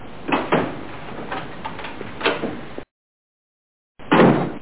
SFX开门声(开门的声音)音效下载
SFX音效